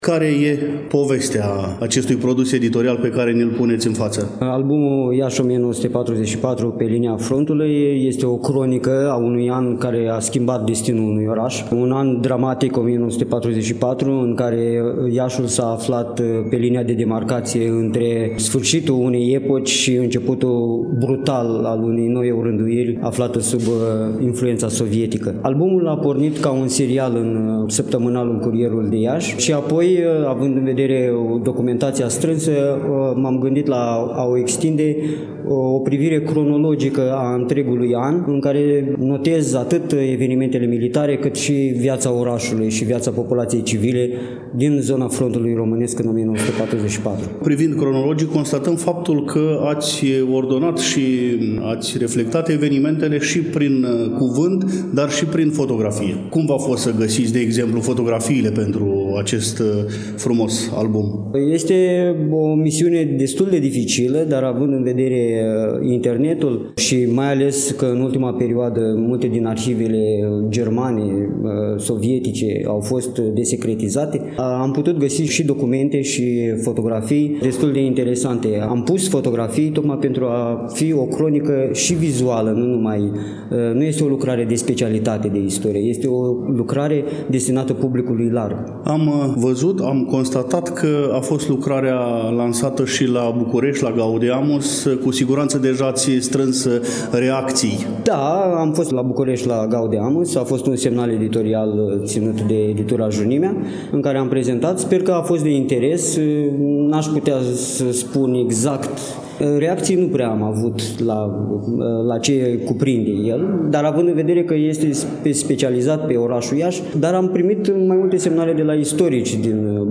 Înaintea lansării, l-am invitat la dialog